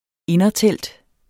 Udtale [ ˈenʌ- ]